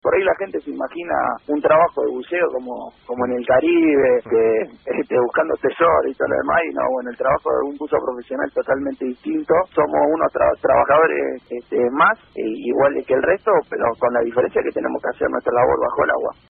Fragmento entrevista